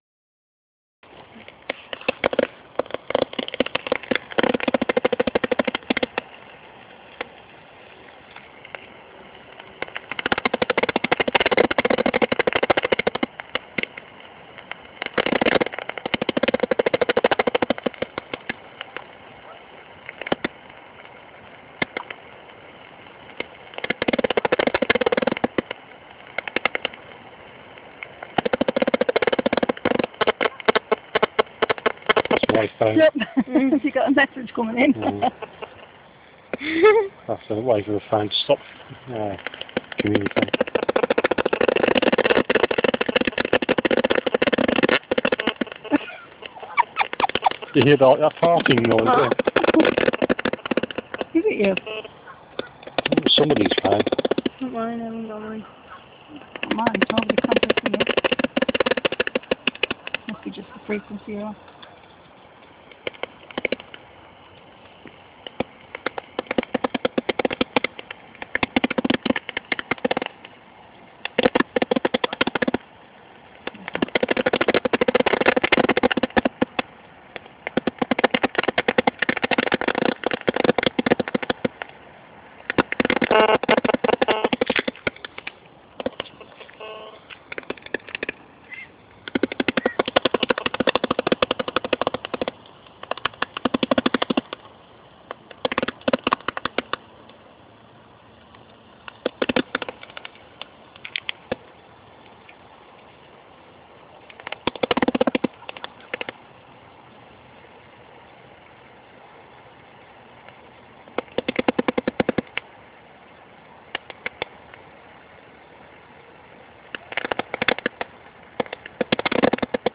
Bat detector
The heterodyne principle is used in this detector.
Some recorded bat calls using this detector at Earlswood Lakes
batrec1.wav